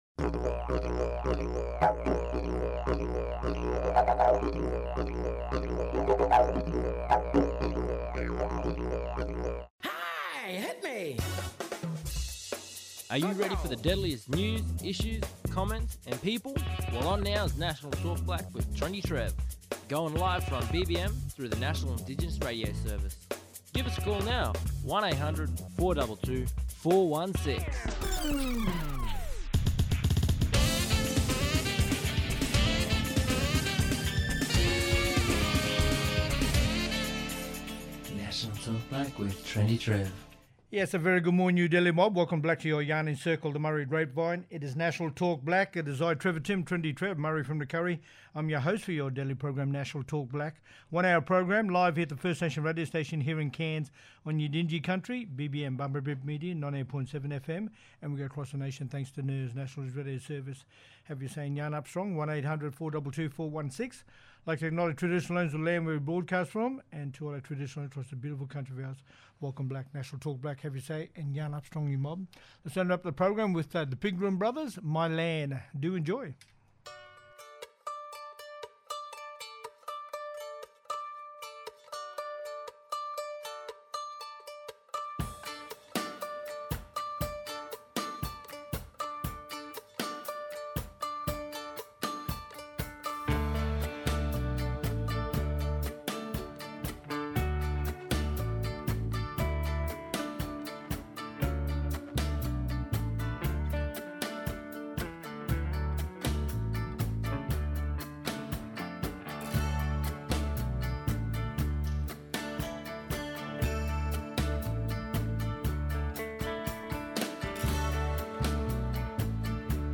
On todays National Talk Black via NIRS – National Indigenous Radio Service we have: